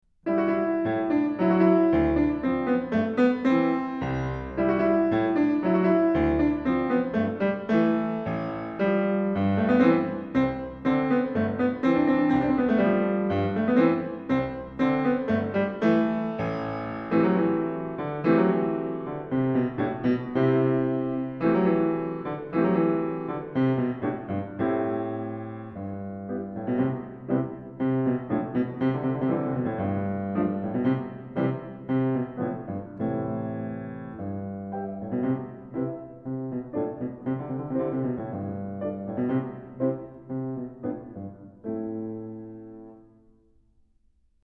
Sonatina para piano